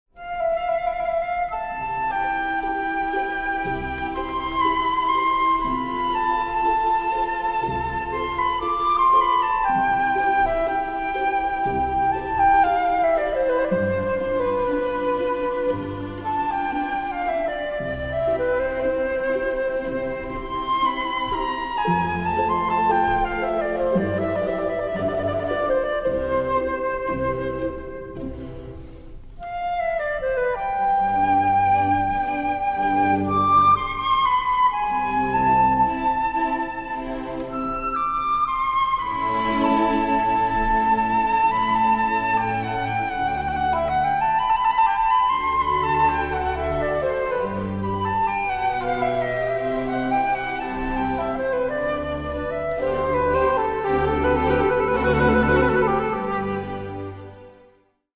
Stamitz_Andante.mp3 Seguiamo l'ascolto sullo spartito: troviamo delle note ornamentali, contrassegnate dai numeri nei riquadri rossi. 1: appoggiatura ; 2: acciaccatura ; 3: mordente ; 4: gruppetto ; 5: trillo .